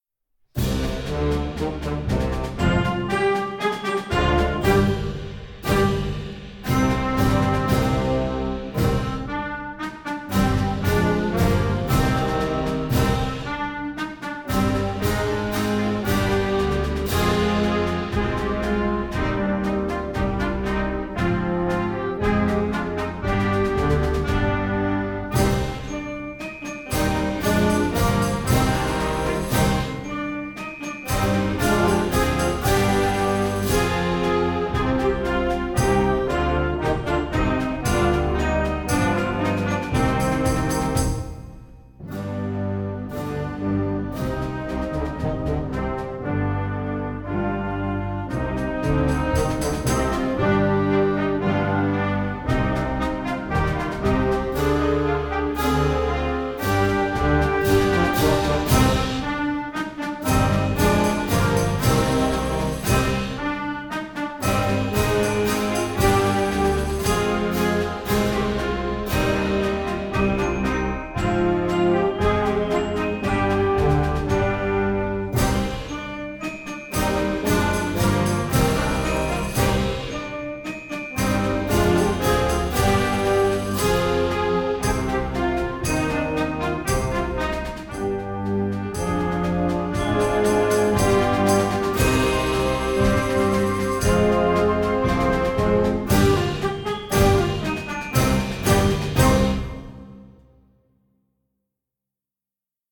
Gattung: Konzertwerk für Jugendblasorchester
Besetzung: Blasorchester
und bietet dramatische, reif klingende Musik